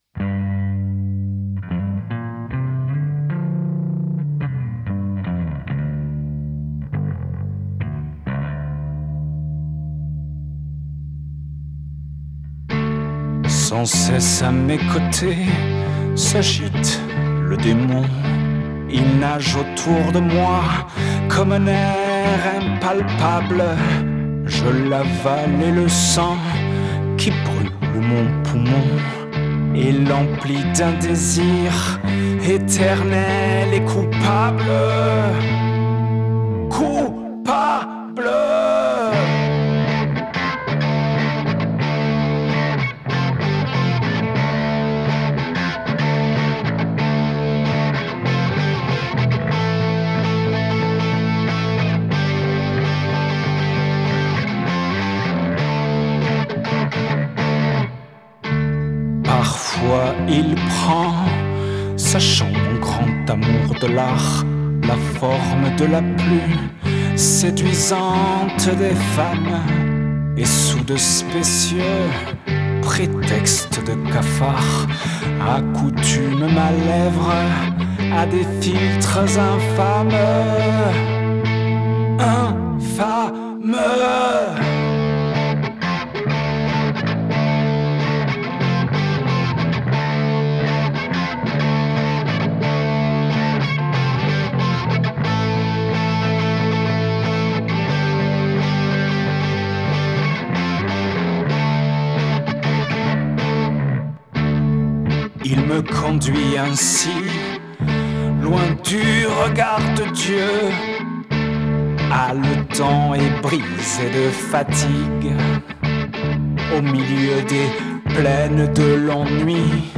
voix et guitare
Guitare additionnelle
Basse
Guitare solo